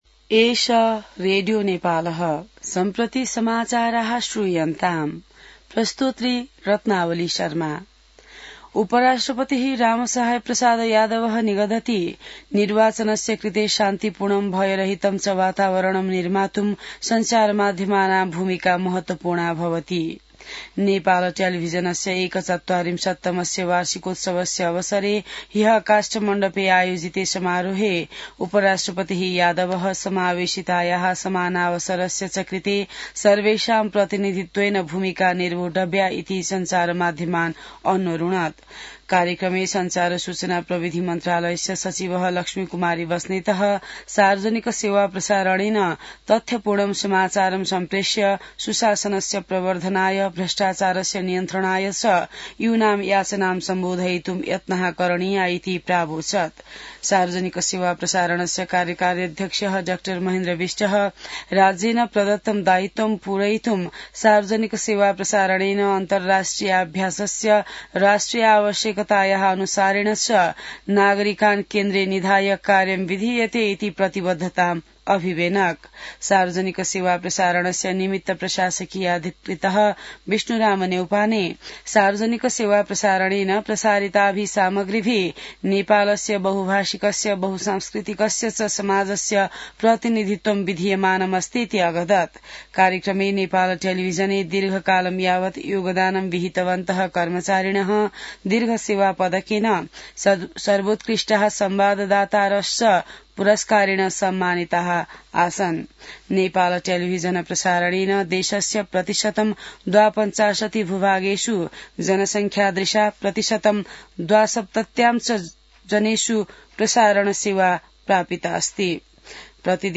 An online outlet of Nepal's national radio broadcaster
संस्कृत समाचार : १८ माघ , २०८२